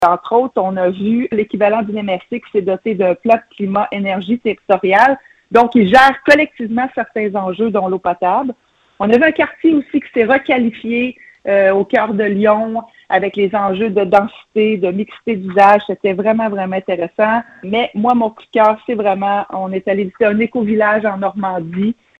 Écouter la préfète de la MRC et mairesse de Nicolet, Geneviève Dubois: